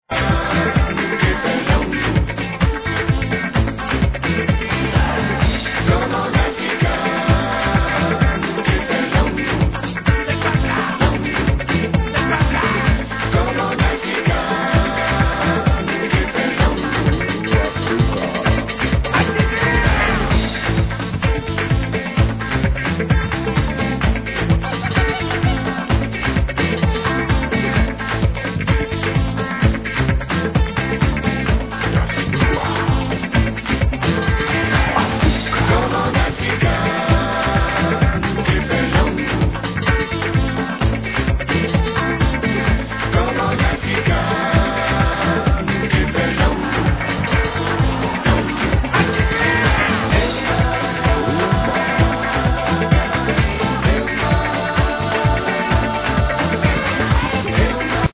Wink ---- Latin House tune from 2004 needs IDing